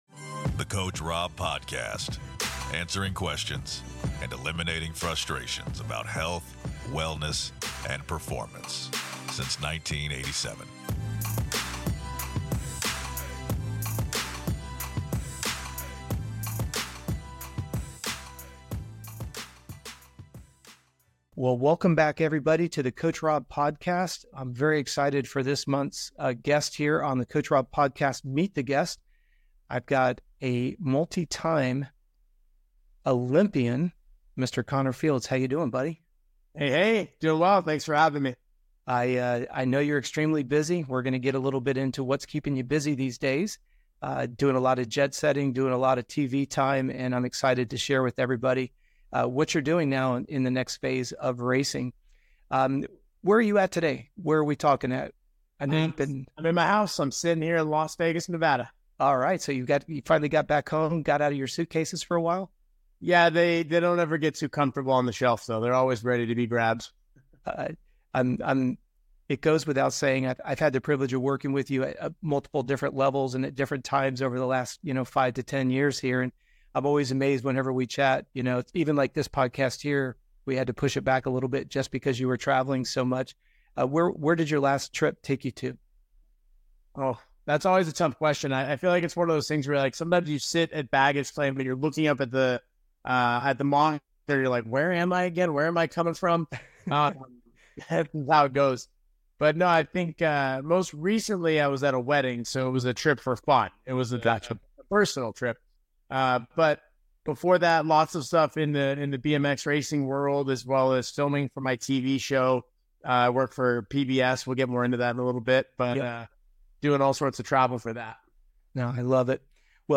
Special Guest Connor Fields, BMX Olympic Gold Medalist